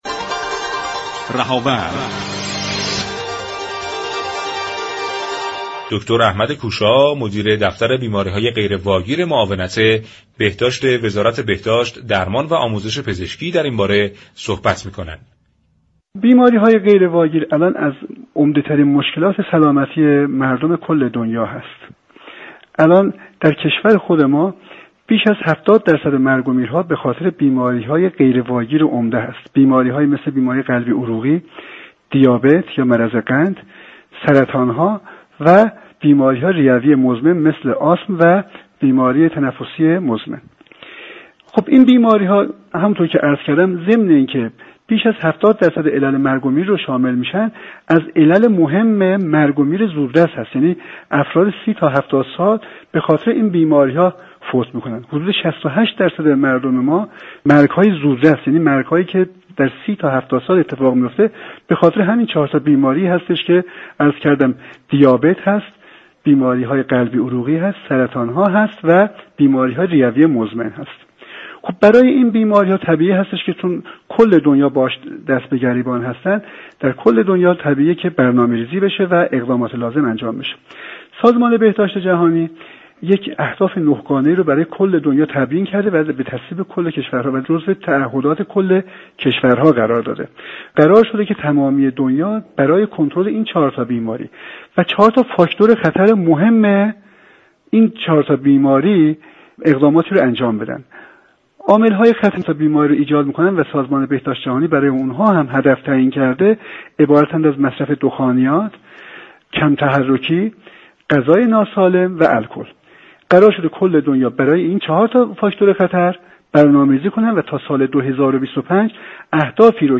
در گفت و گو با برنامه «رهاورد»